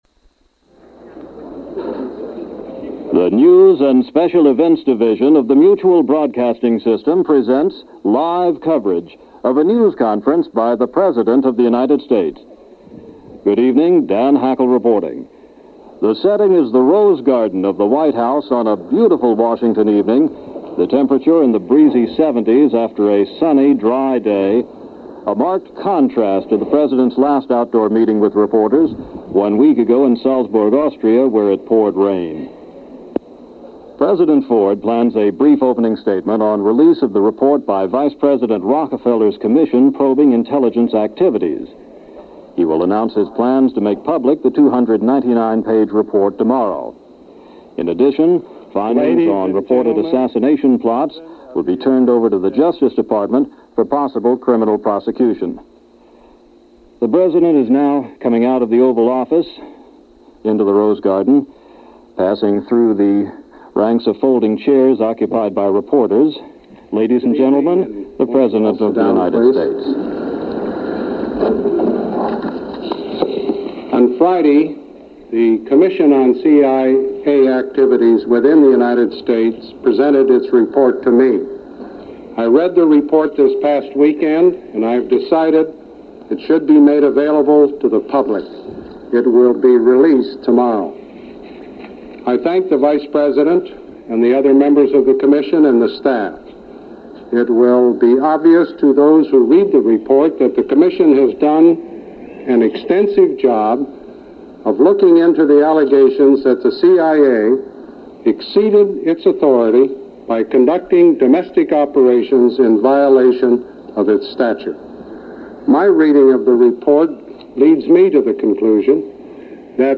President Ford Holds A Press Conference - The CIA -June 9, 1975 - broadcast live from the White House Rose Garden by Mutual News.